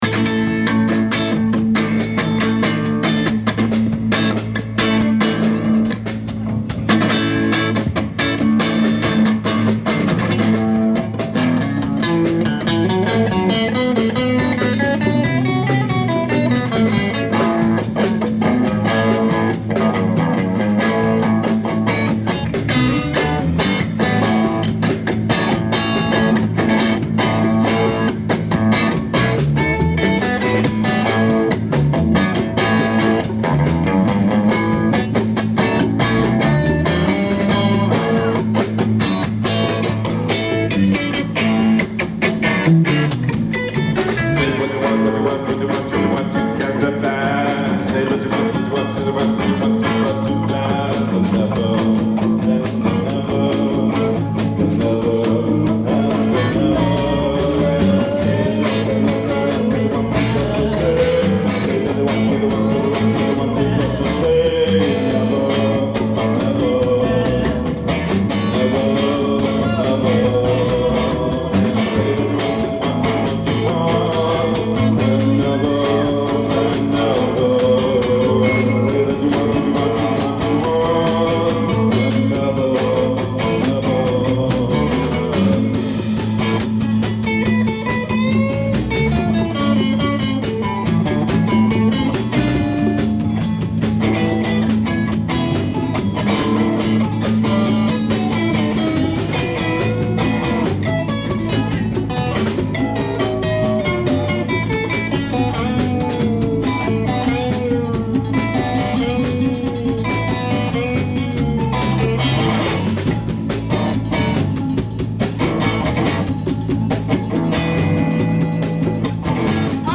Say What You Want, But Don't Do That guitar
bass
drums
voice
ALL MUSIC IS IMPROVISED ON SITE